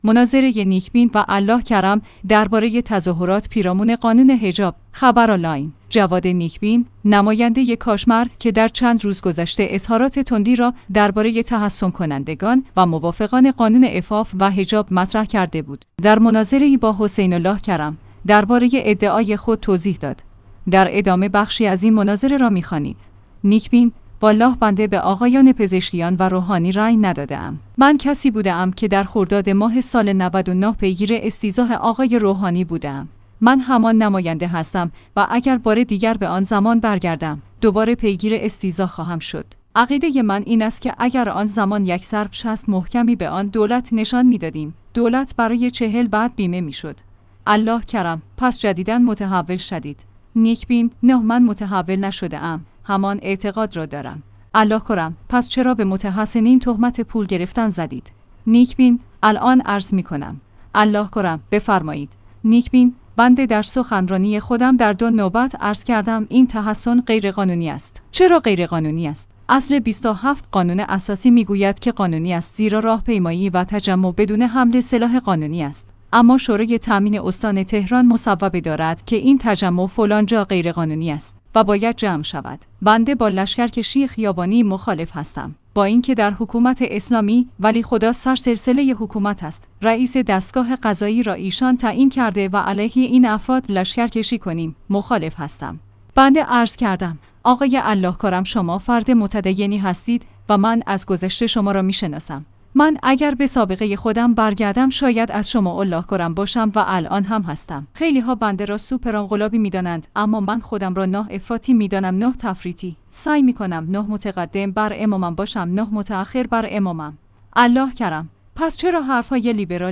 مناظره